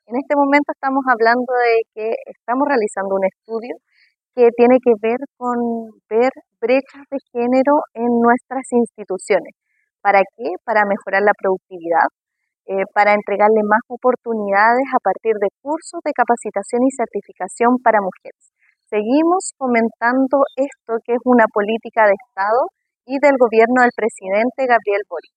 El Museo Interactivo de Osorno fue el lugar de encuentro para desarrollar la Segunda Mesa Regional de Energía Más Mujeres y Capital Humano, una iniciativa desarrollada en conjunto entre el Ministerio de Energía y la Agencia de Sostenibilidad Energética.